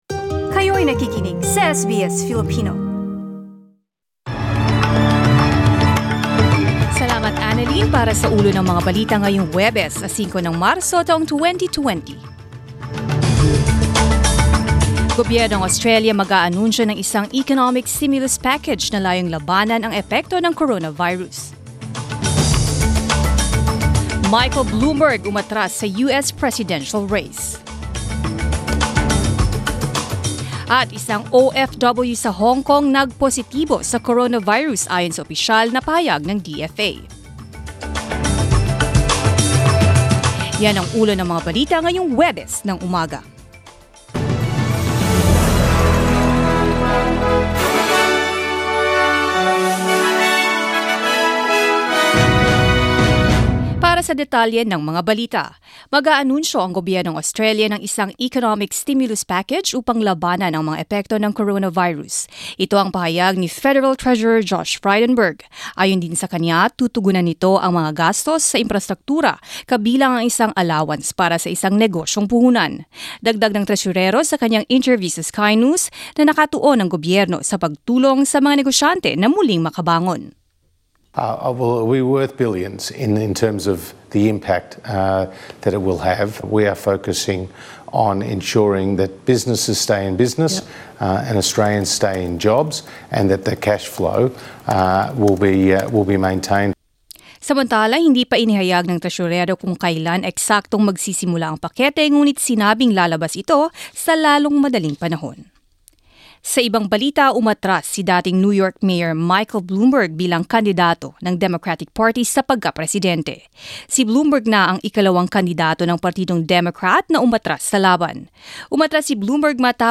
SBS News in Filipino, Thursday 05 March